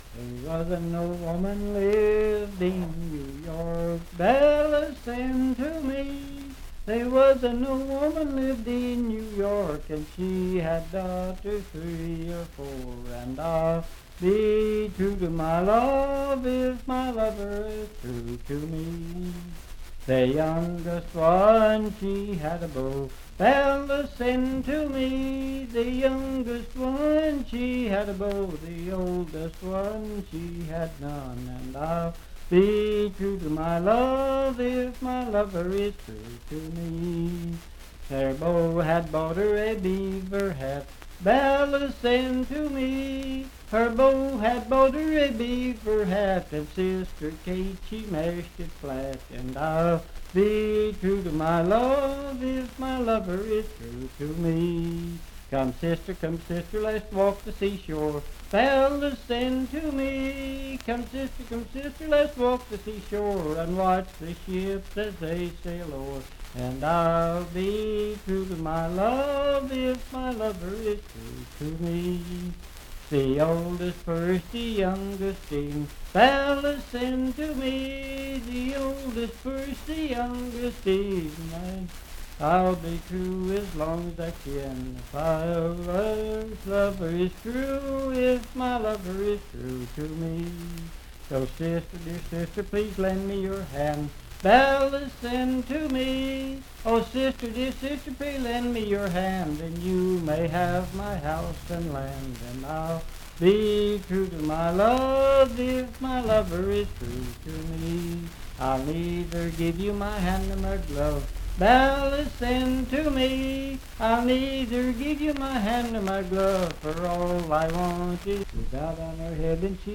Unaccompanied vocal music
Verse-refrain 9(6).
Voice (sung)